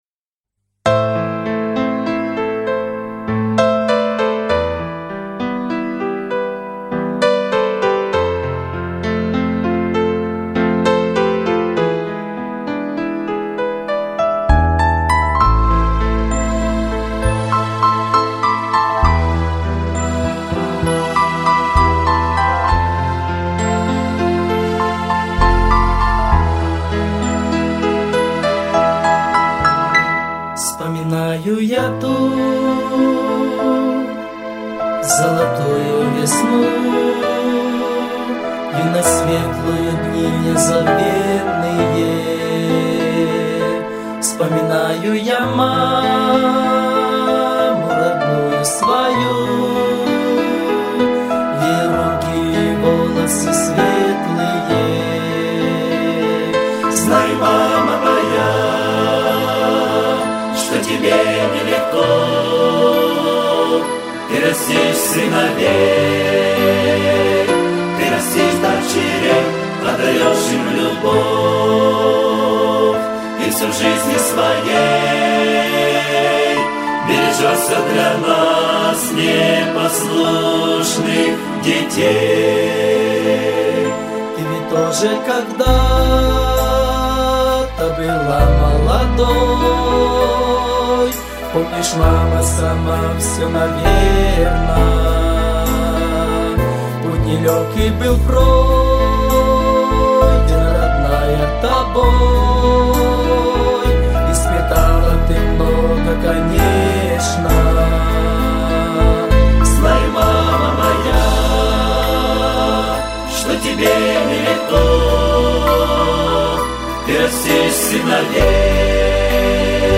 672 просмотра 1018 прослушиваний 97 скачиваний BPM: 75